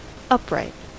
Ääntäminen
US : IPA : [ʌp.ɹaɪt]
IPA : /ˈʌpraɪt/